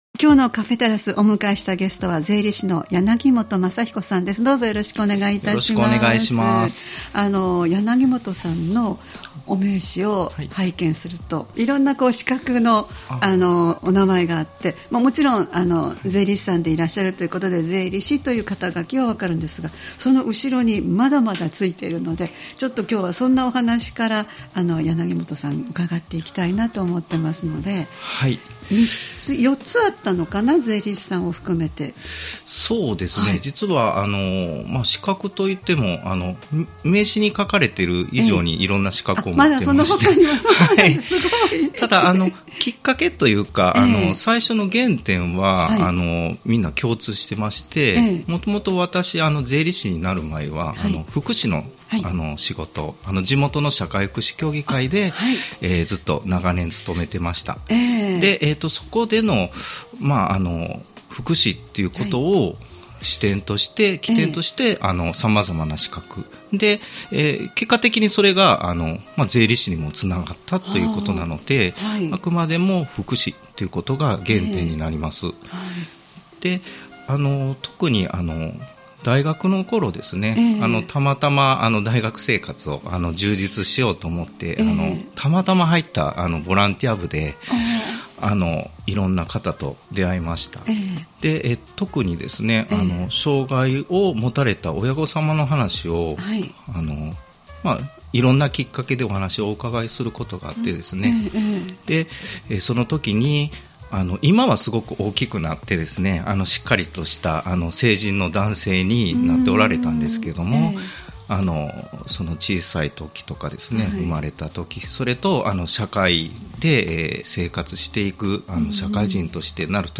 様々な方をスタジオにお迎えするトーク番組「カフェテラス」（再生ボタン▶を押すと放送が始まります）